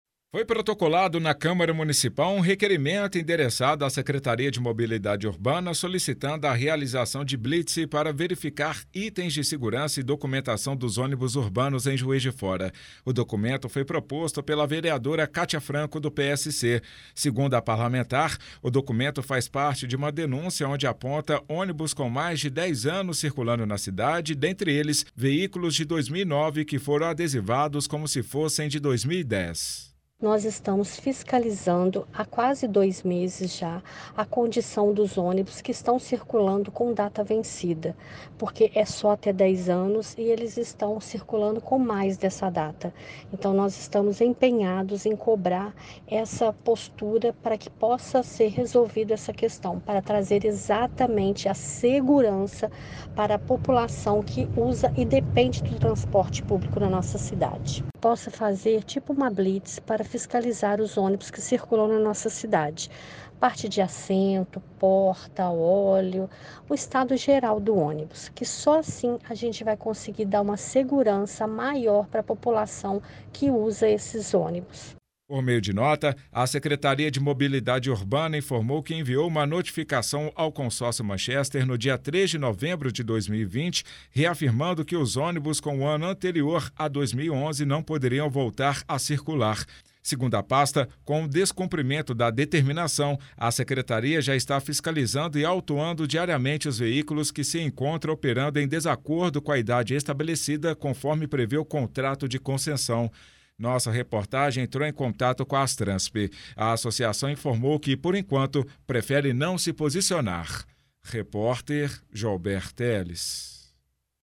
Os detalhes e os posicionamentos da Secretaria e da Astransp estão na reportagem da FM Itatiaia.